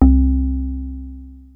JUP.8 C2   3.wav